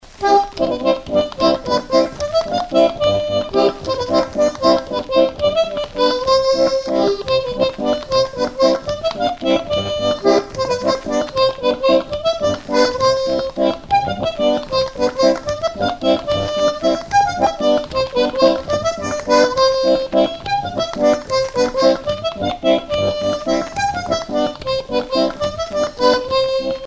Mémoires et Patrimoines vivants - RaddO est une base de données d'archives iconographiques et sonores.
Bourrée
Région ou province Morvan
danse : bourree